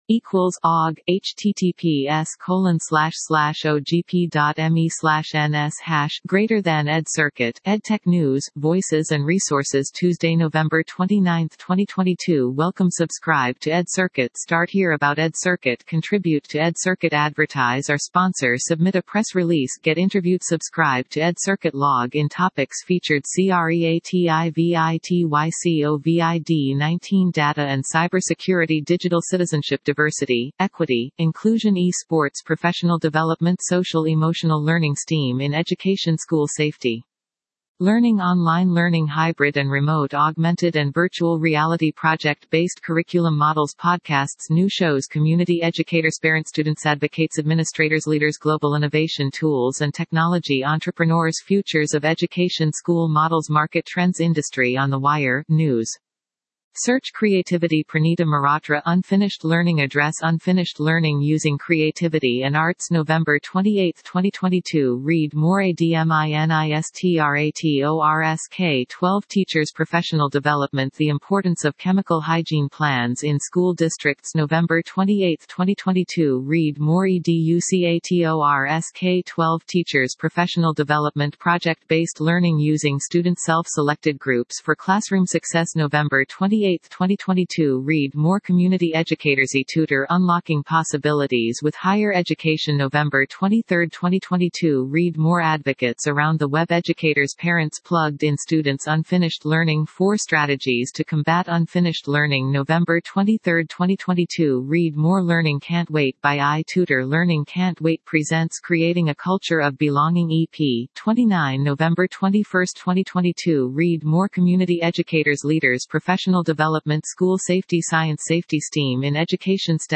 An interview